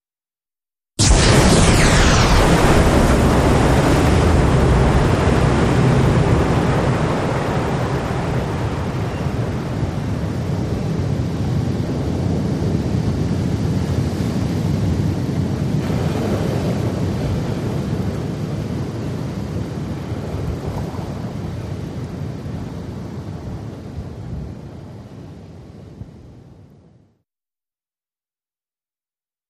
Depth Charge
Explosion, Surface Perspective Depth Charge Multiple Ver. 1